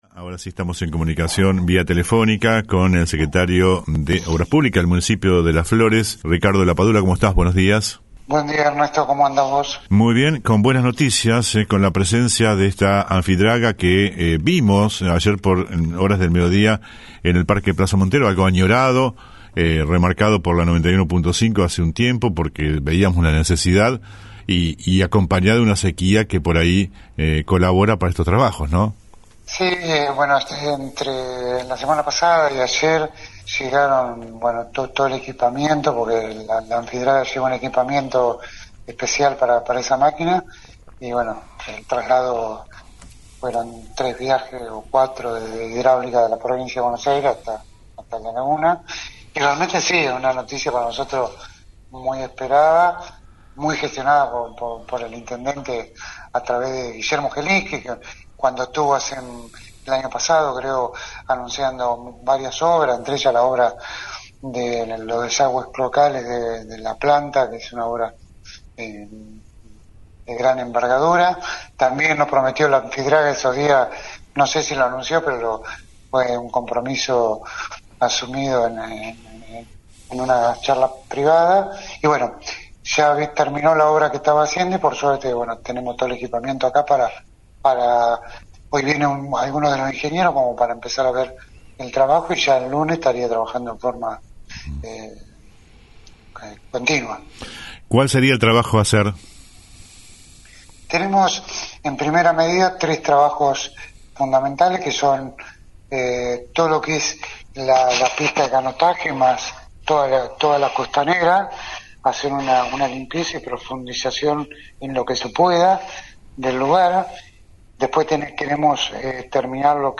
Ayer observamos la presencia de la misma con dos profesionales que estarán en principio por 4 meses. Por este tema, conversamos con el Sec. de Obras públicas, Ricardo Lapadula.